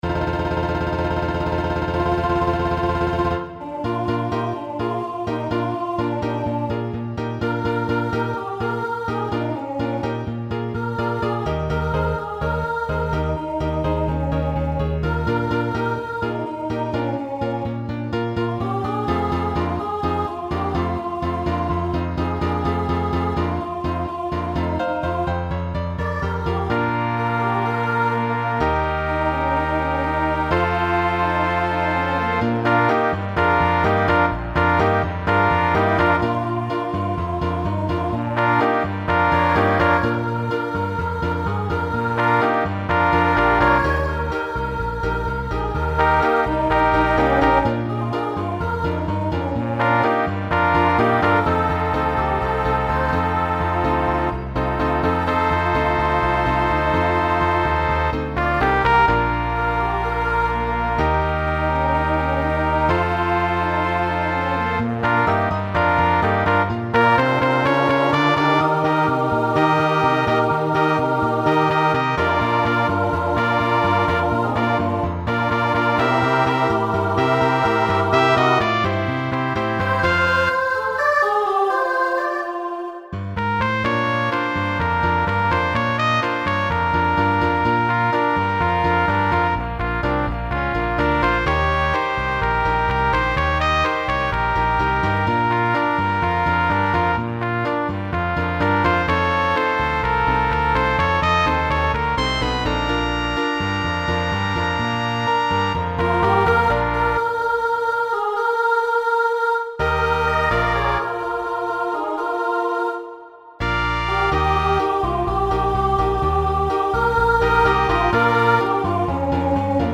Voicing SSA Instrumental combo Genre Country , Rock